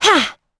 Hilda-Vox_Attack3.wav